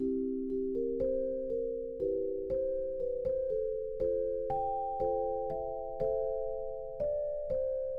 老式Hip Hop音乐中的 "Lofi Boom Bap "和 "Bells Celesta"。
Tag: 120 bpm Hip Hop Loops Bells Loops 1.35 MB wav Key : Unknown